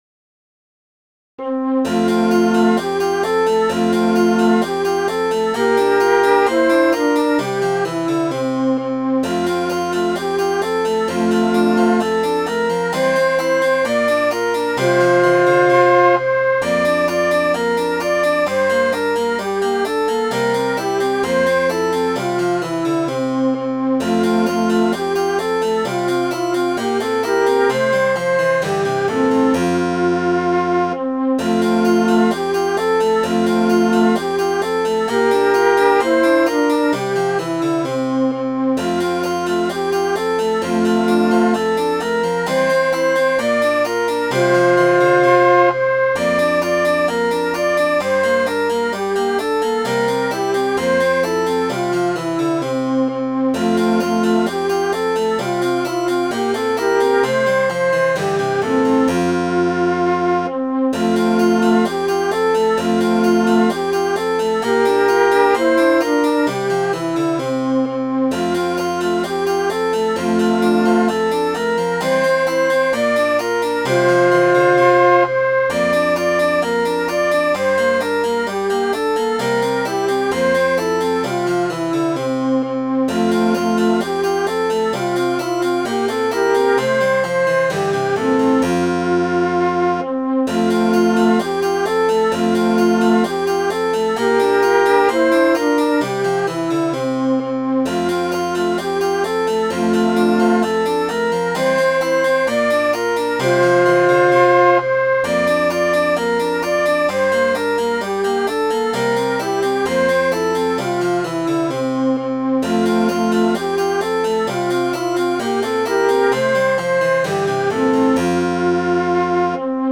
Midi File, Lyrics and Information to All Round My Hat